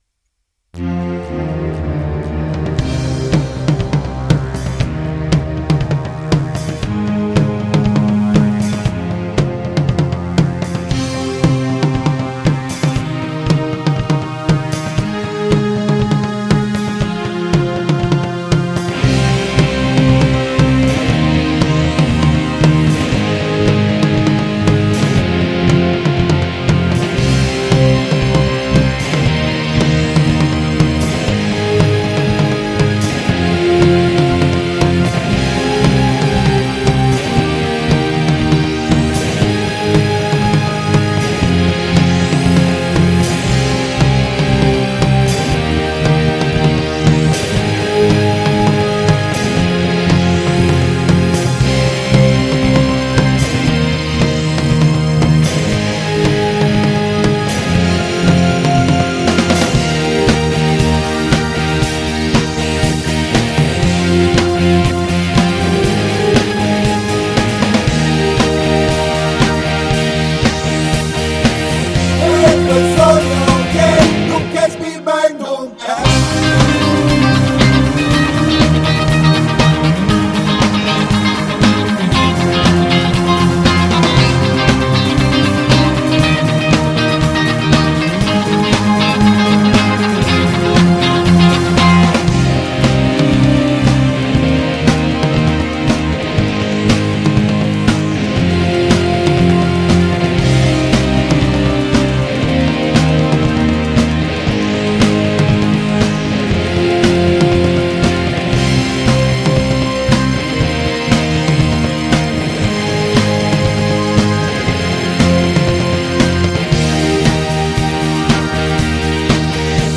voce e basso
voce e tastiera
voce e chitarra
batteria.